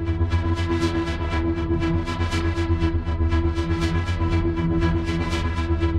Index of /musicradar/dystopian-drone-samples/Tempo Loops/120bpm
DD_TempoDroneB_120-E.wav